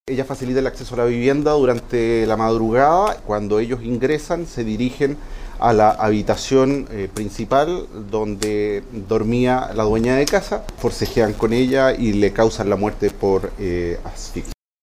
El fiscal Omar Mérida señaló parte de la dinámica con que lograron establecer la participación de la menor de edad, que es la hija de uno de los hijos de la víctima fatal.